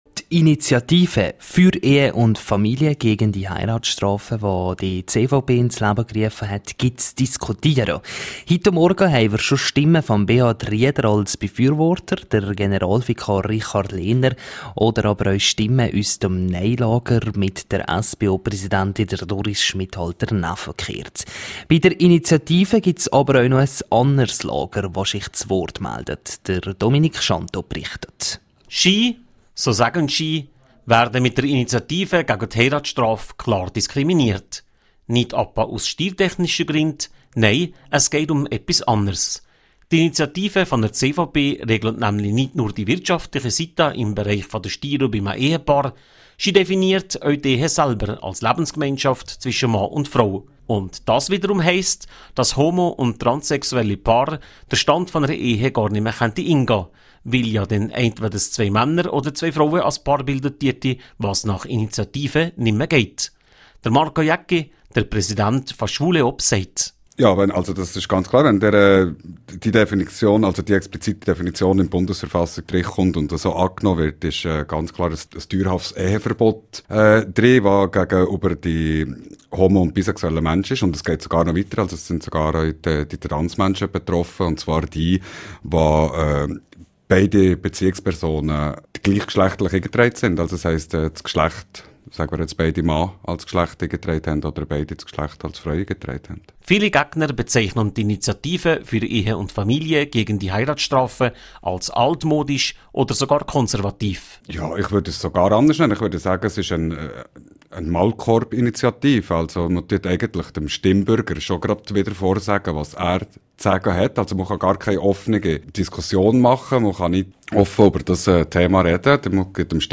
13610_News.mp3